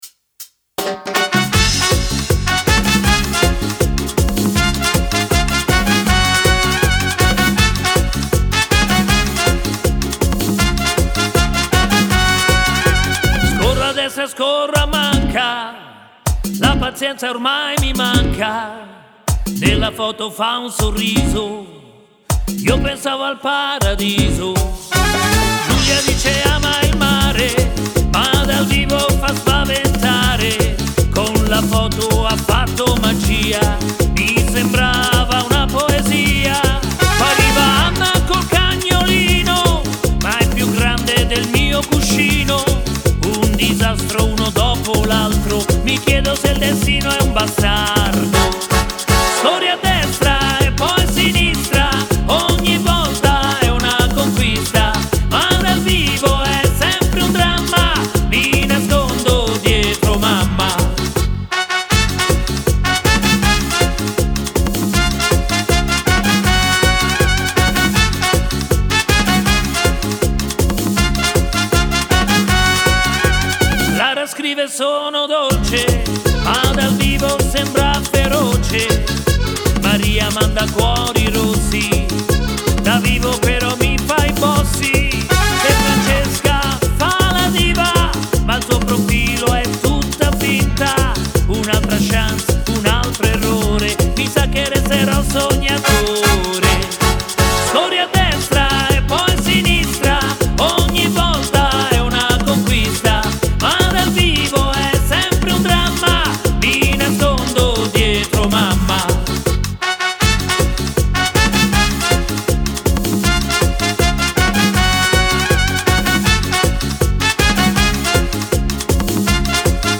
(Merengue)
Simpatico merengue